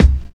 Kicks
WU_BD_066.wav